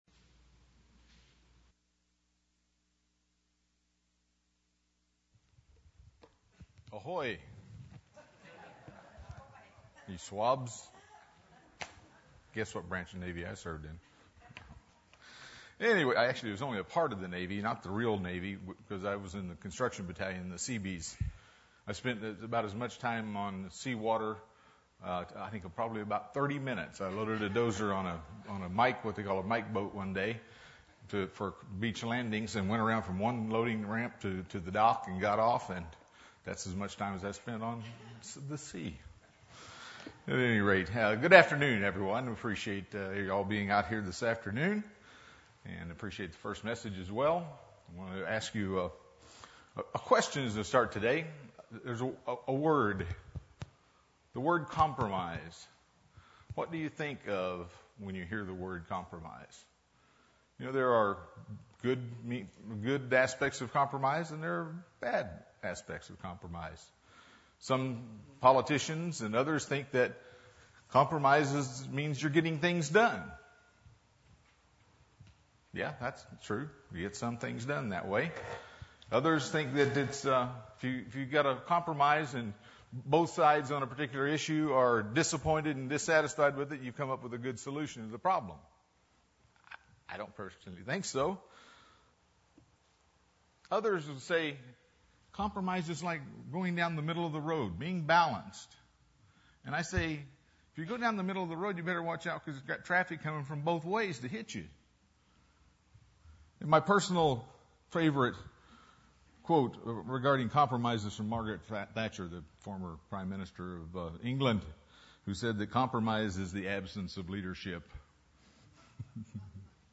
UCG Sermon Studying the bible?
Given in San Diego, CA